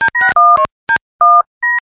描述：通过按特定顺序按手机按钮创建的“歌曲”。
Tag: 电话 手机按键 手机